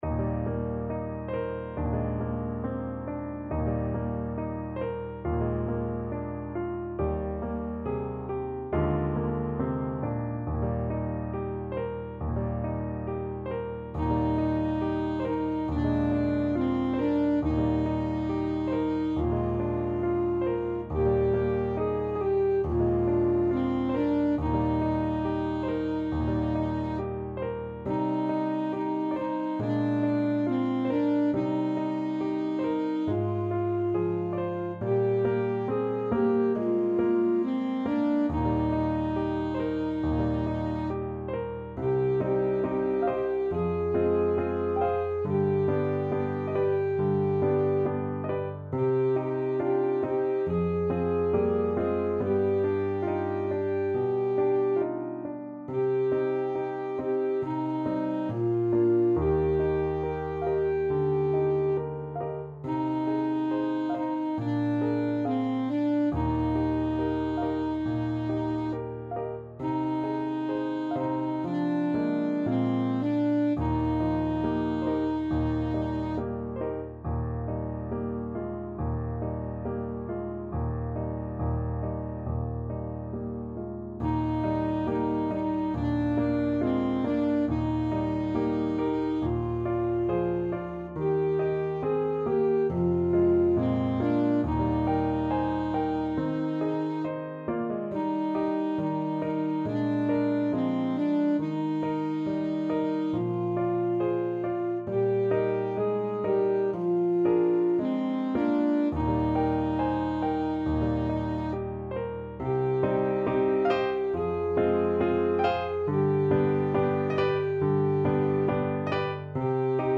Alto Saxophone version
Alto Saxophone
4/4 (View more 4/4 Music)
~ = 69 Andante tranquillo
Classical (View more Classical Saxophone Music)